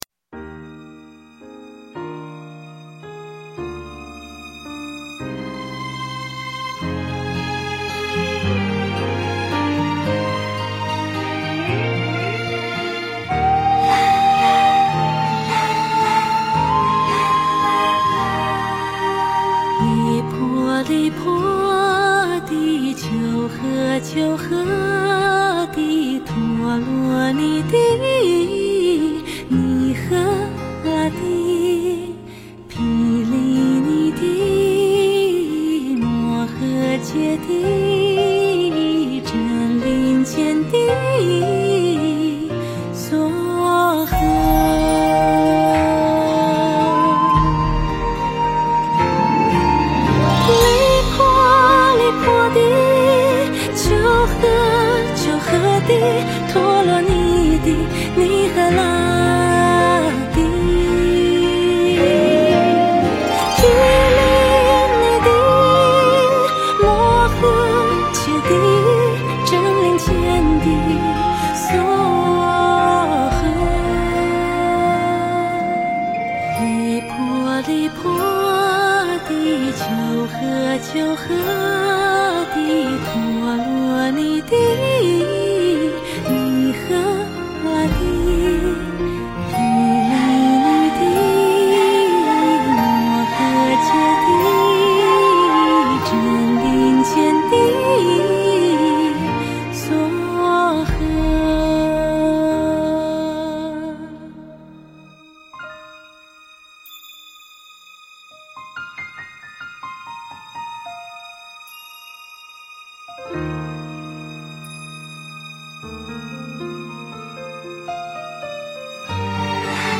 佛音 诵经 佛教音乐 返回列表 上一篇： 绿色大地 下一篇： 祈福 相关文章 惜缘--何禹萱 惜缘--何禹萱...